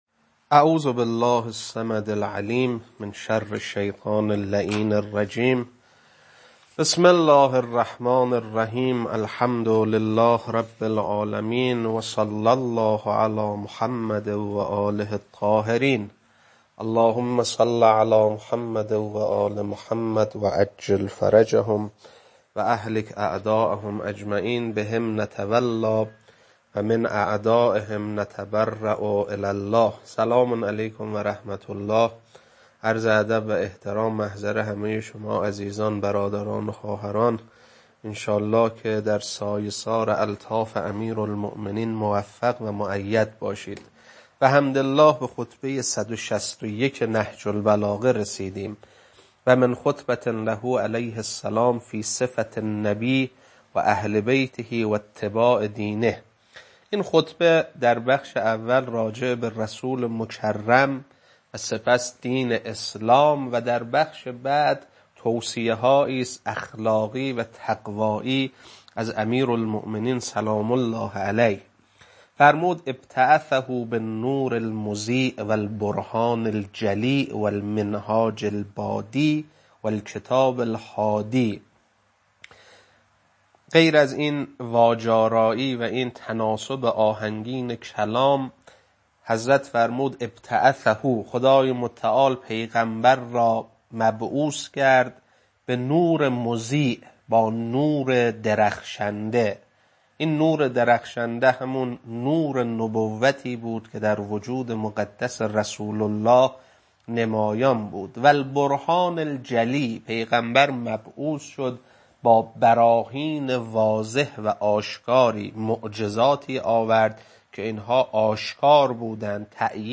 خطبه-161.mp3